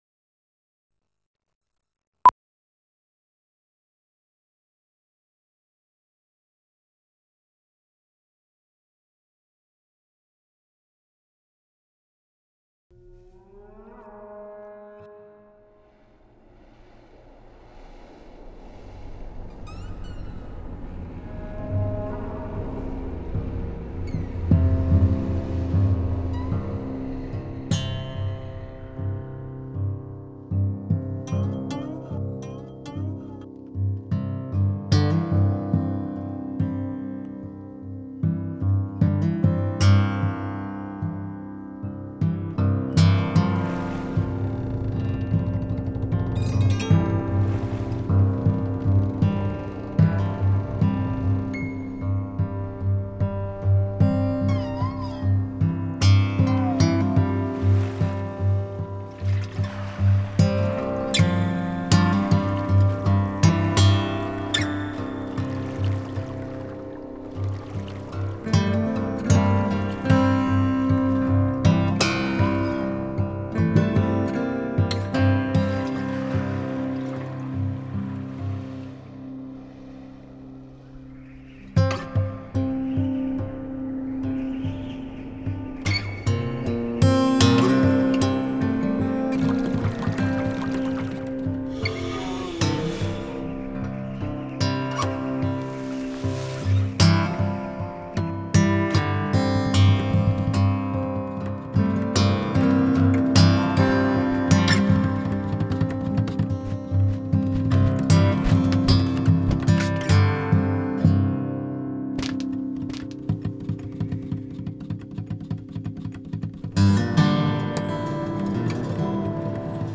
the music/soundscape all created with one guitar